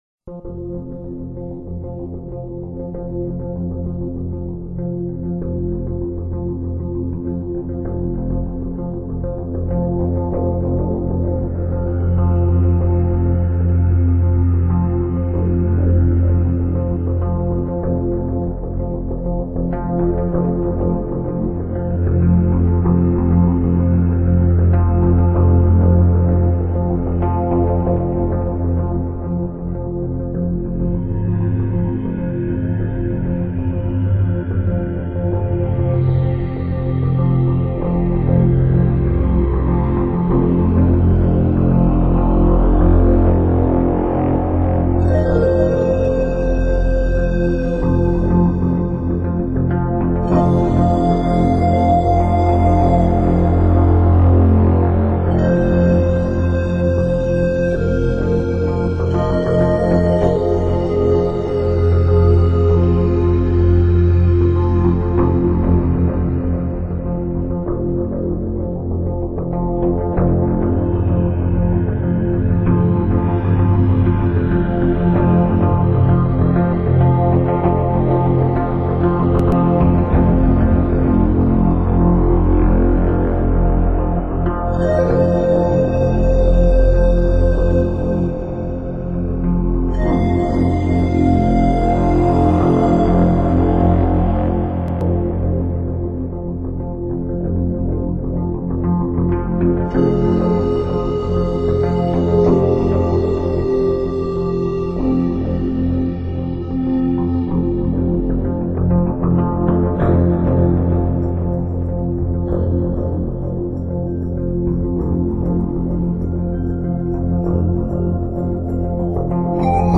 主题鲜明的钢琴演奏专辑，琢磨出钢琴静谧、激情的双面美感